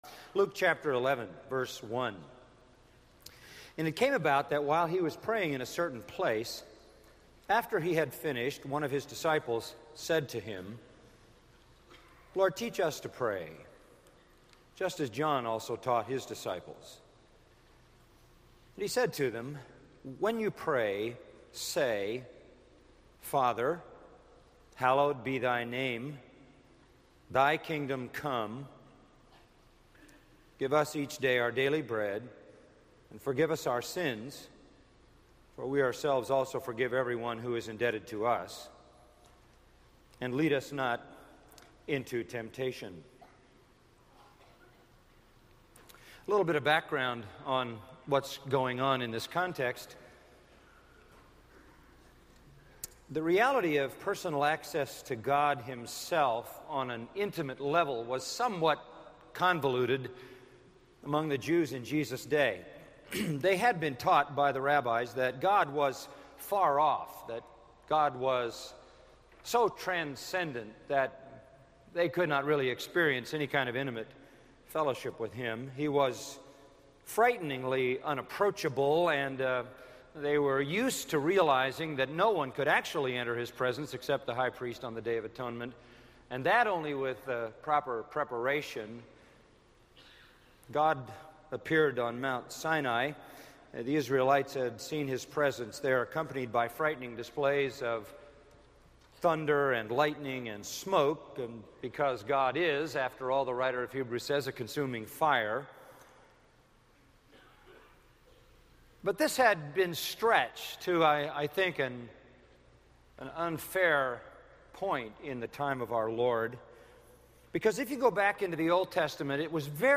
This message is from our 2005 National Conference, 5 Keys to Spiritual Growth: • 5 Keys to Spiritual Growth: 2005 National …